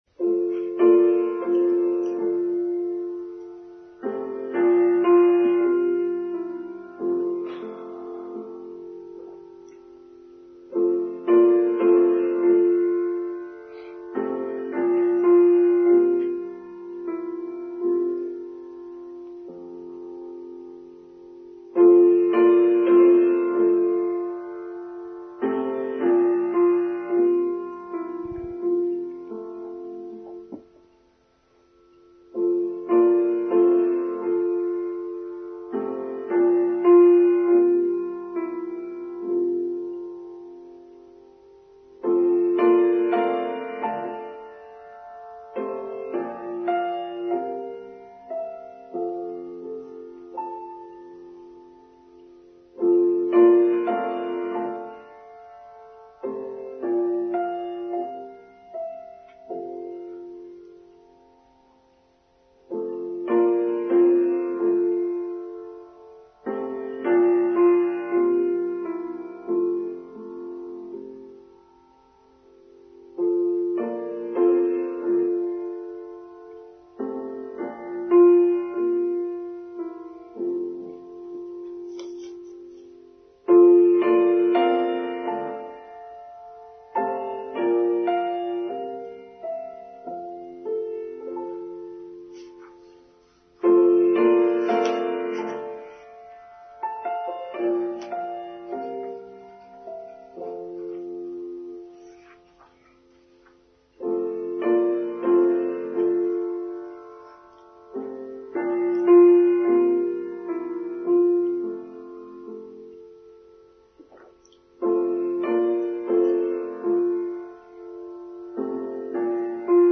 Earth Day 2023: Online Service for Sunday 23rd April 2023